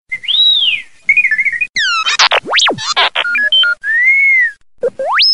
R2D2-sound-HIingtone